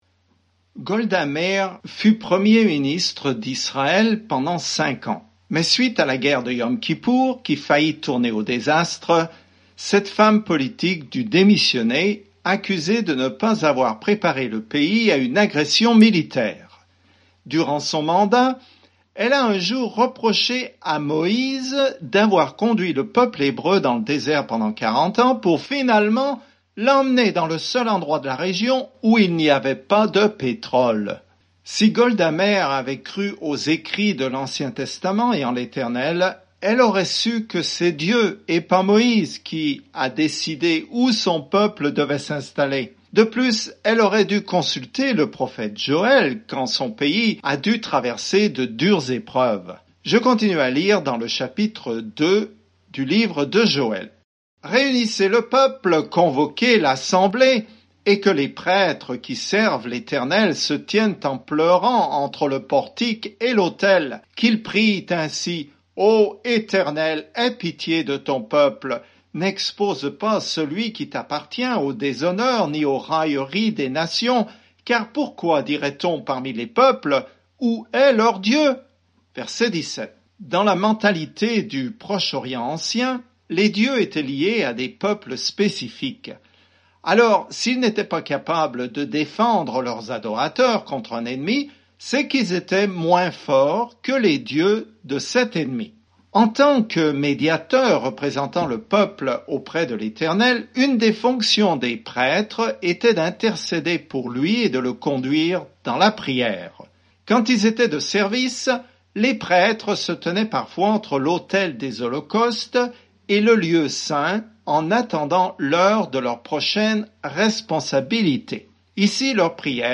Écritures Joël 2:15-27 Jour 5 Commencer ce plan Jour 7 À propos de ce plan Dieu envoie une invasion de sauterelles pour juger Israël, mais derrière son jugement se cache la description d’un futur « jour du Seigneur » prophétique où Dieu aura enfin son mot à dire. Parcourez quotidiennement Joël en écoutant l’étude audio et en lisant certains versets de la parole de Dieu.